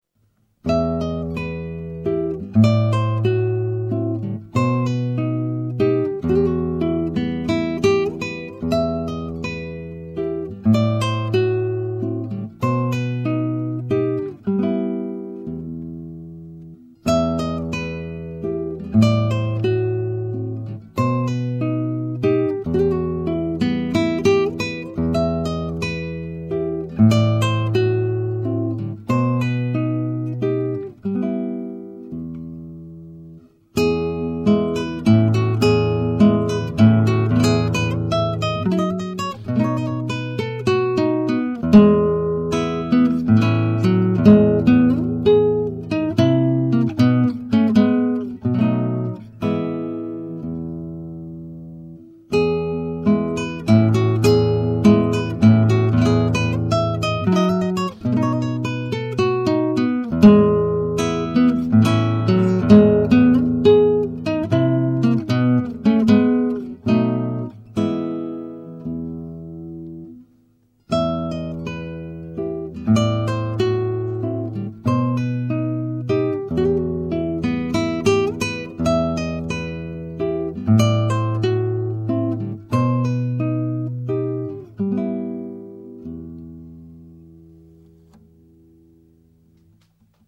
Daarnaast componeerde hij ook voor gitaar, meest korte genrestukken.